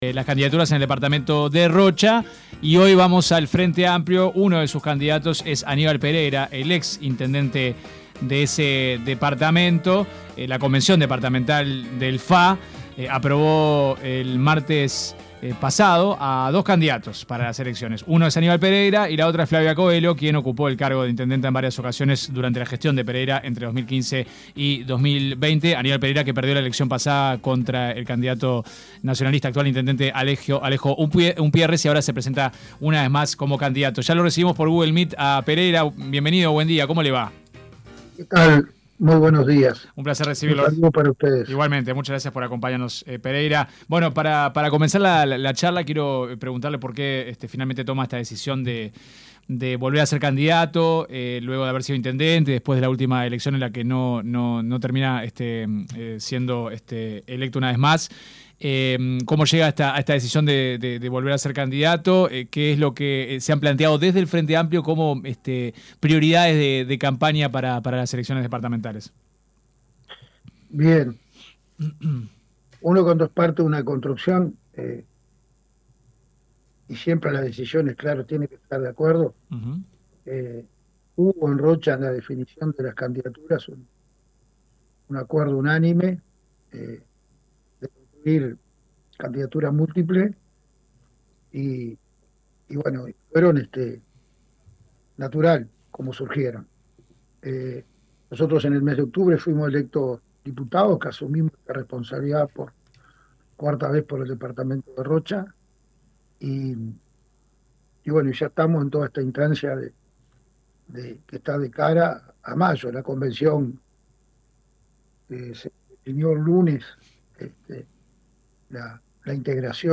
El diputado electo y candidato a la Intendencia de Rocha por el Frente Amplio, Aníbal Pereyra en entrevista con 970 Noticias dijo que el Tribunal de Cuentas realizó 11.040 observaciones a las resoluciones de la Intendencia encabezada por Alejo Umpiérrez del Partido Nacional.